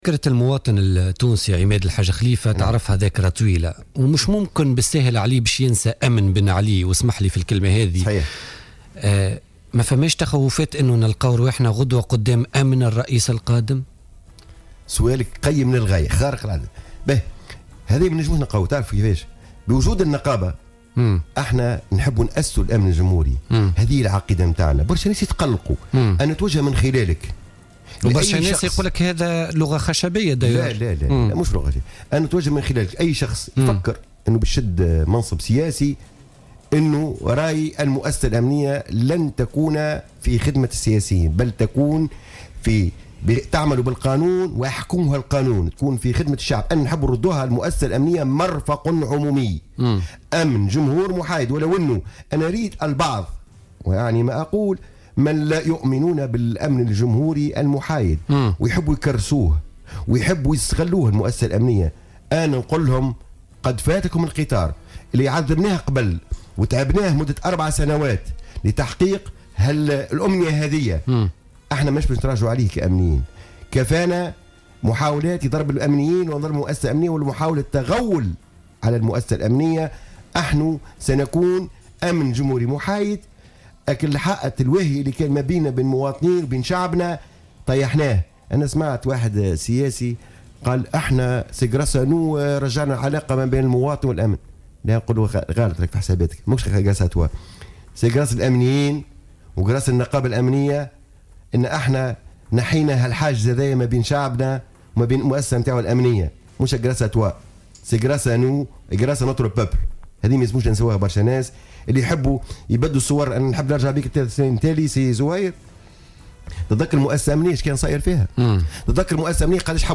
ضيف برنامج "بوليتيكا" اليوم